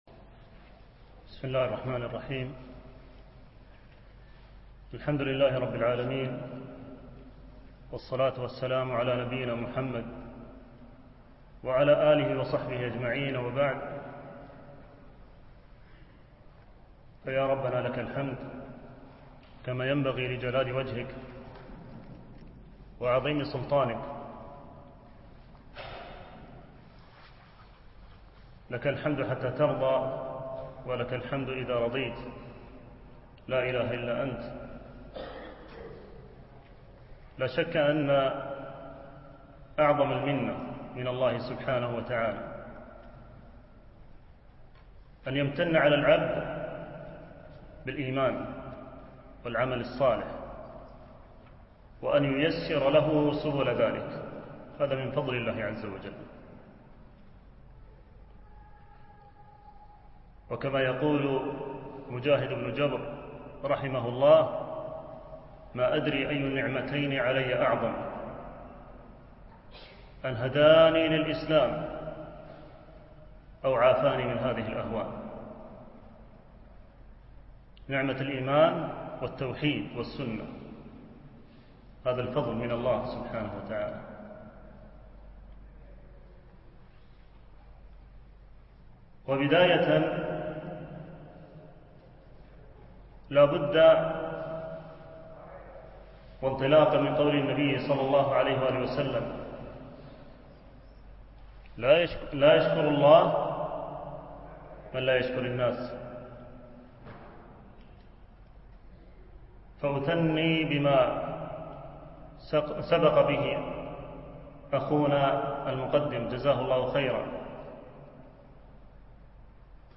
شرح منظومة في نواقض الإسلام - الدرس الأول - الجزء الأول